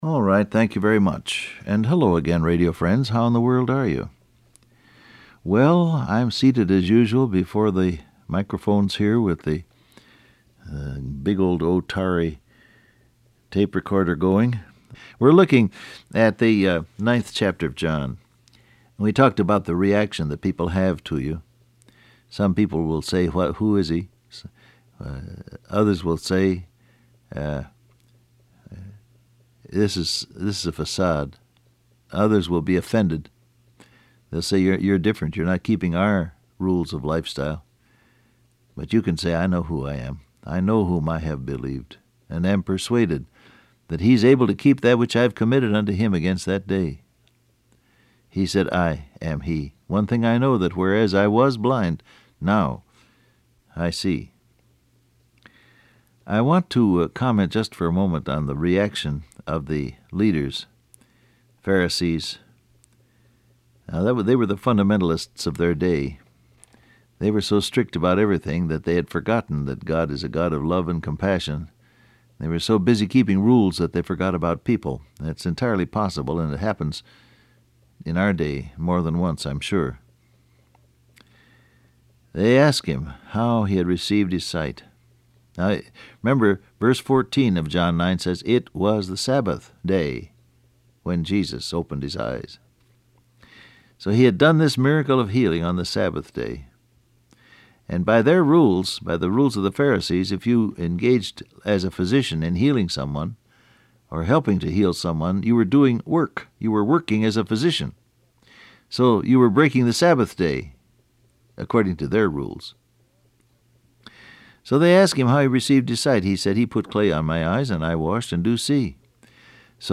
Well, I’m seated as usual before the microphones here with the big old Otari tape recorder going.